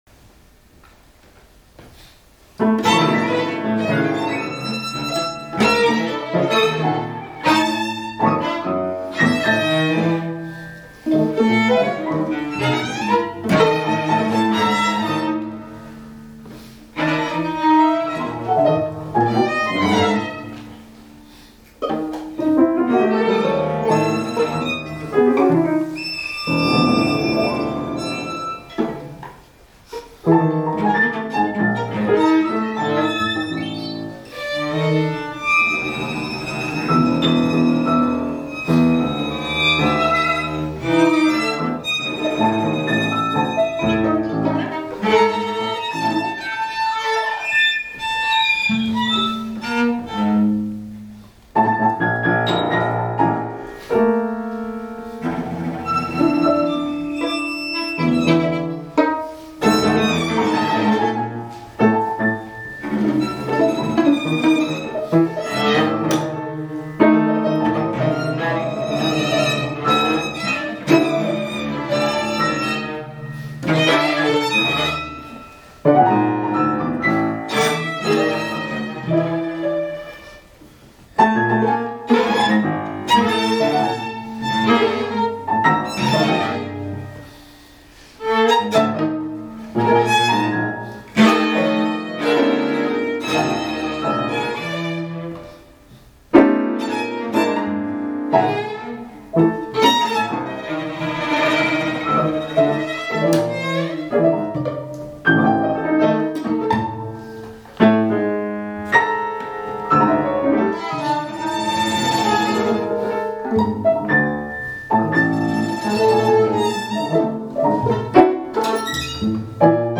Piano quintet